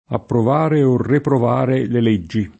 riprovare v. («disapprovare»); riprovo [ripr0vo] — in questo senso il provando e riprovando [prov#ndo e rriprov#ndo] di Dante — ant. forme più o meno latineggianti reprobare: reprobo [repr0bo]; e reprovare: reprovo [repr0vo]: è stato dalla fortuna reprobato [H St#to dalla fort2na reprob#to] (Machiavelli); approvare o reprovare le leggi [